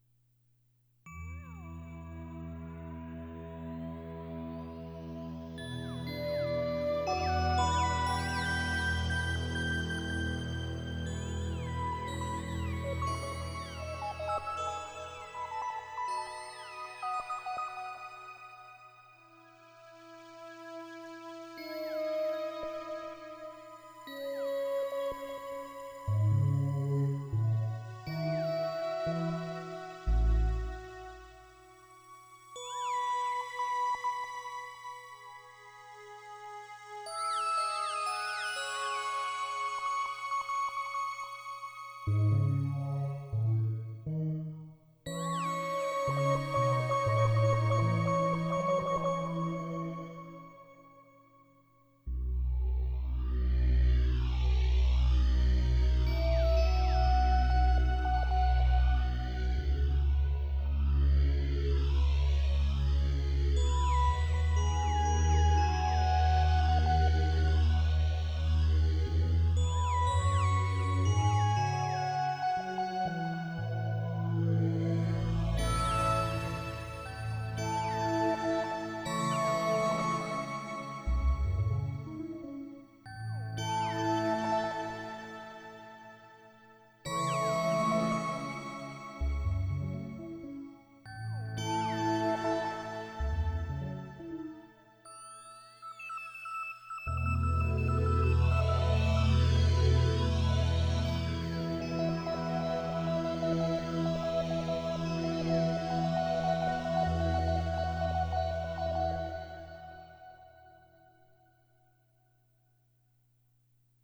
Sound Effects Three Synthesizers